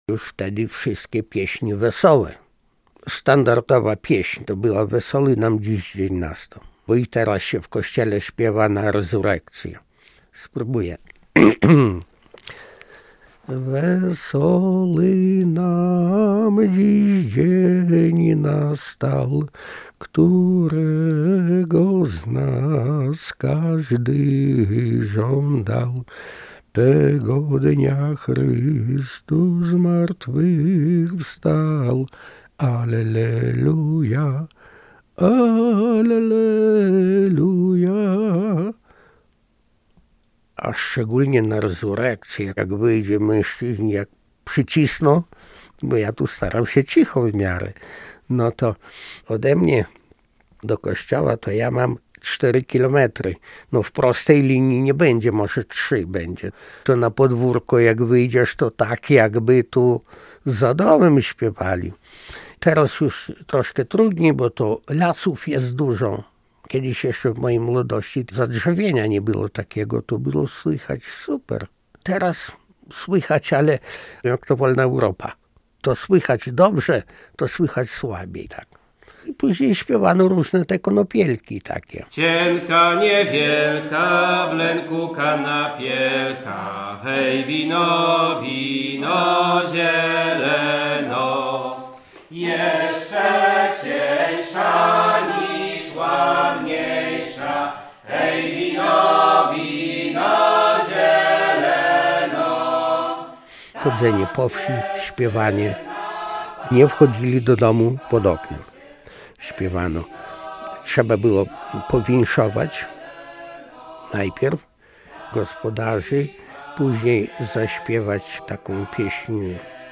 W słychać śpiew